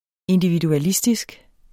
Udtale [ endividuaˈlisdisg ]